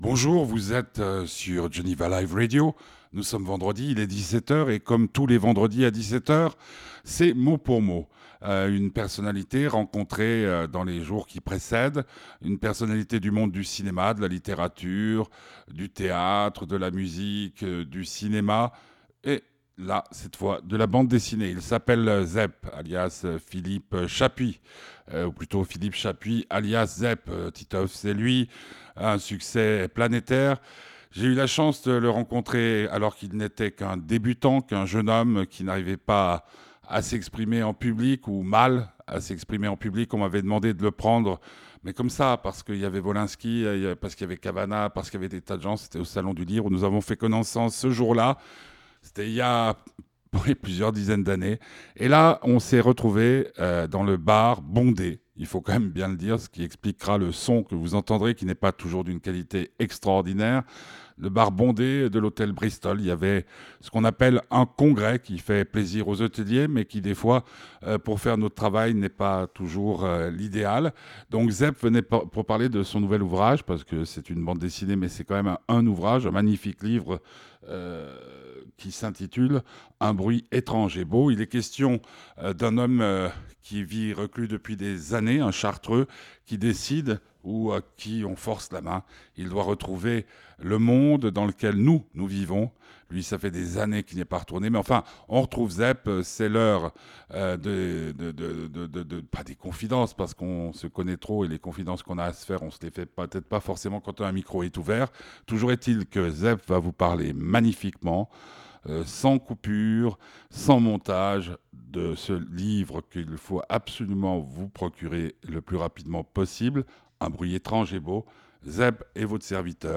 Interview de ZEP